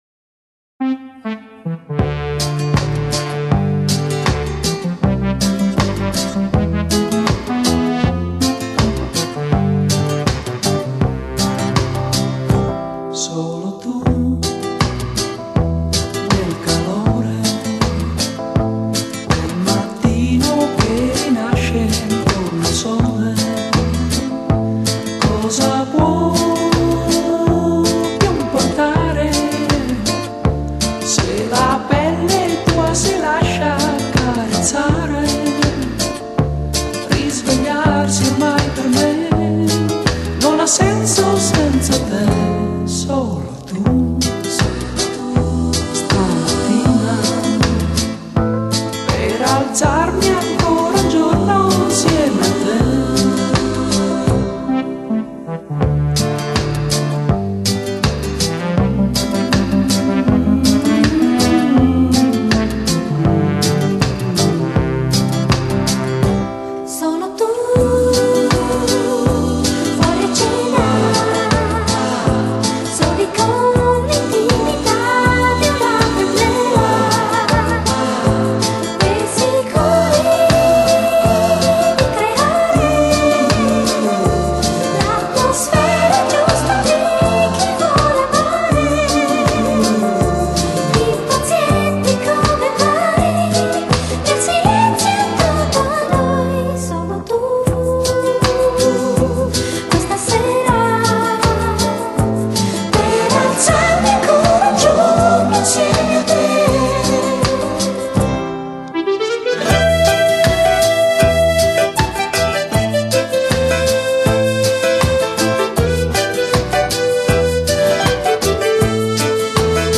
Genre: Electronic, Pop ,Italian Pop
尽管偏离前卫已有一段距离，仍是属于听起来很舒服的流行摇滚乐。